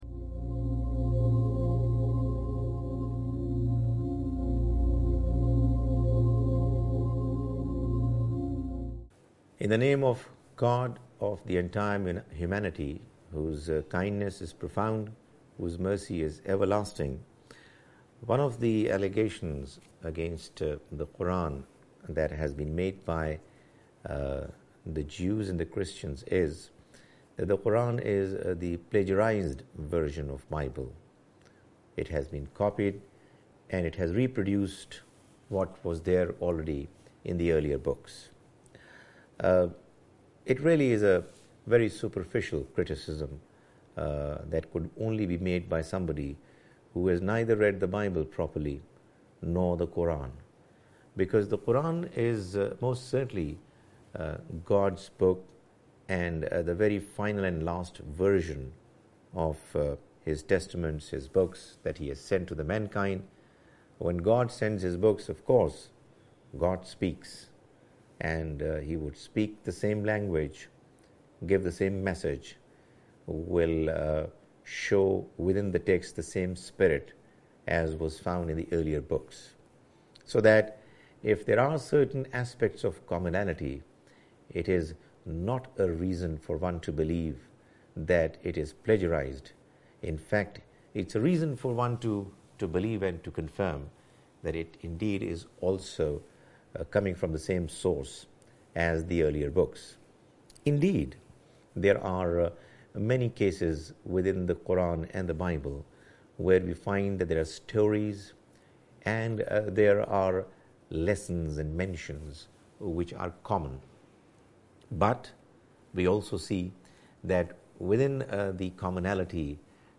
A lecture series